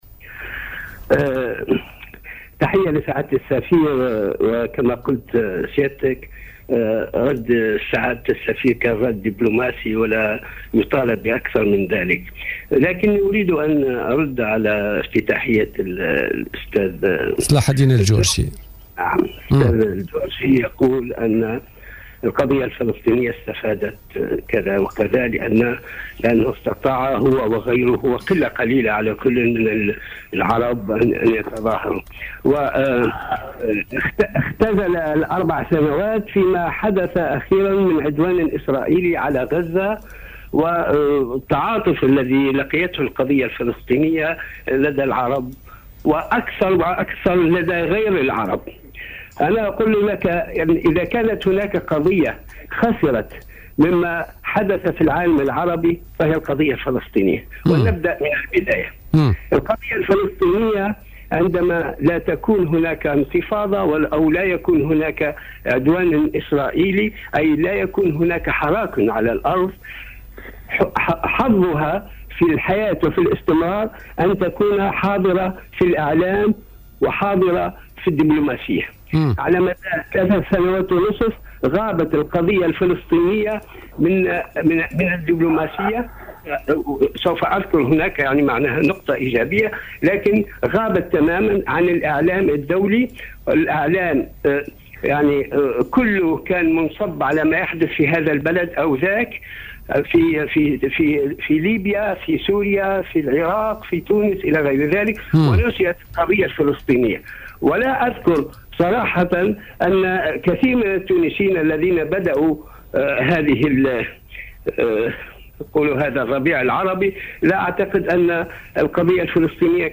في مداخلة له اليوم الجمعة في برنامج "بوليتيكا"